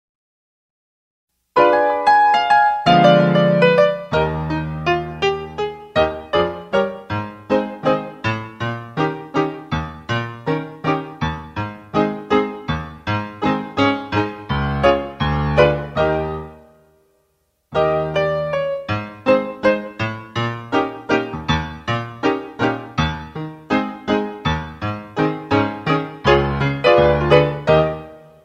Instrumental Tracks.